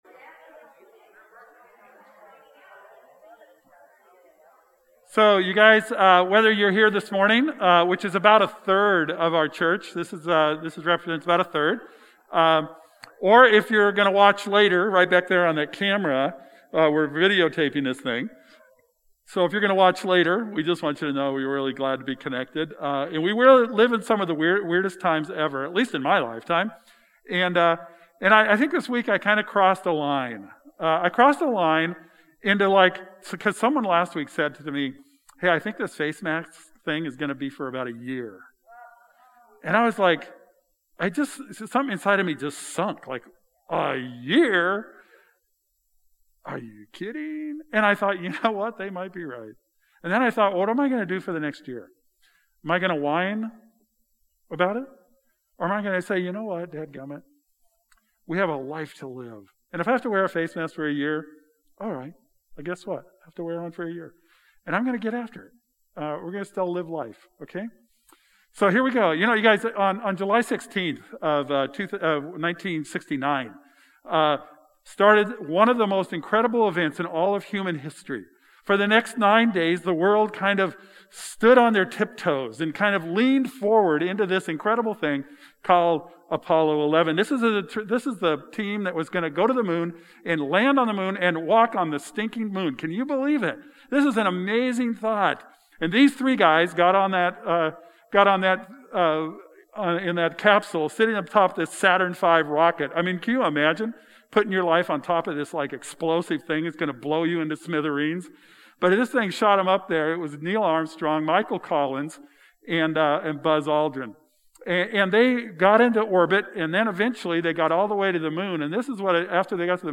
Sunday Service: June 21, 2020